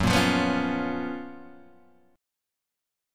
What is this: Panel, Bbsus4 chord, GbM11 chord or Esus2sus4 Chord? GbM11 chord